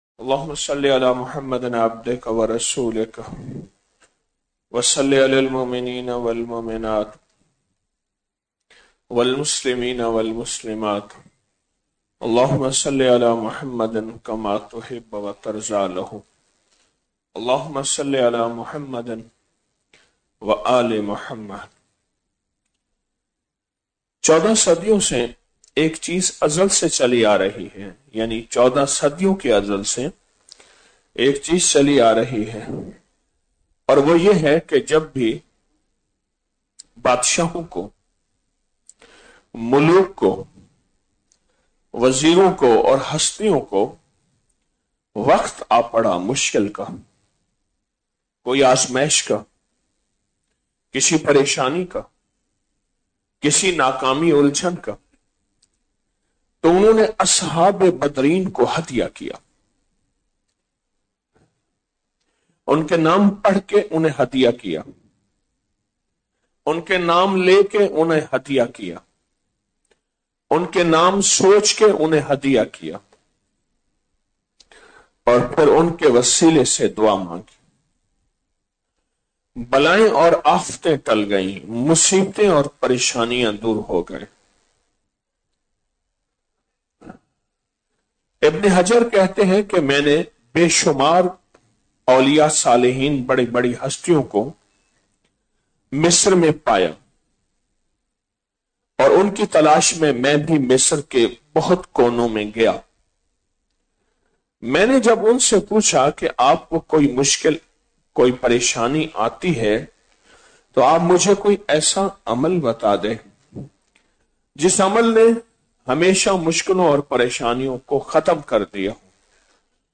313 ہستیاں جن کے وسیلے سے ہر دعا قبول|| 17 رمضان المبارک بعد نماز تراویح - 06 مارچ 2026ء